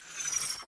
powerdown.mp3